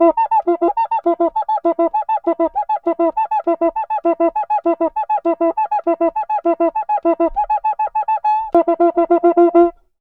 Cuica_Samba 100_1.wav